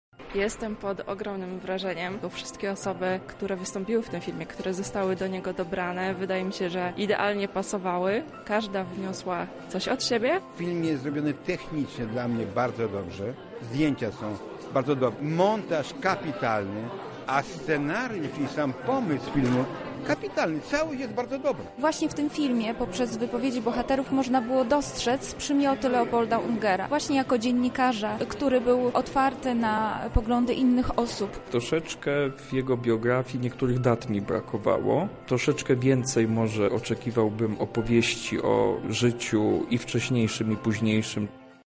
Po seansie uczestniczyliśmy w spotkaniu z twórcami dokumentu i zaproszonymi gośćmi.